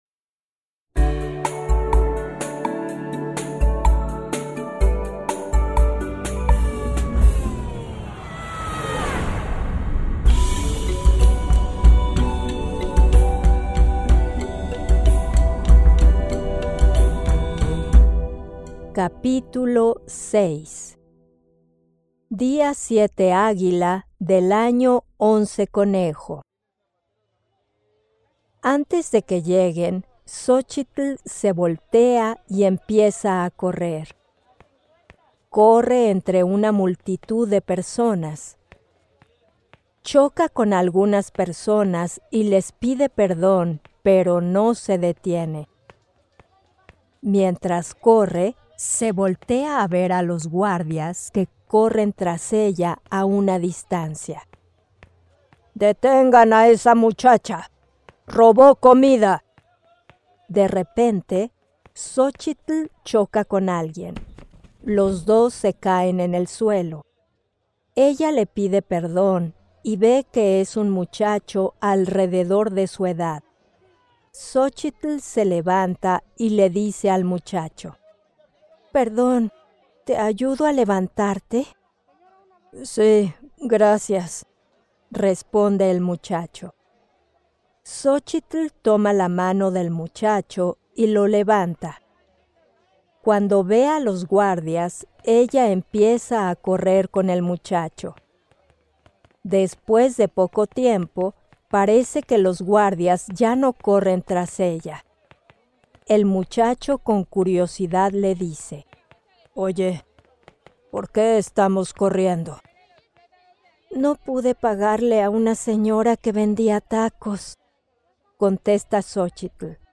Each audio book contains original music, sound effects and voice acting from Native Spanish speakers to ensure a memorable experience for your students!
Un viaje en el tiempo Audiobook sample: